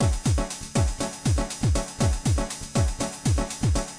额外的滚动hihats被分层到120bpm的循环中。感觉相当慢而不是快，而且很放松。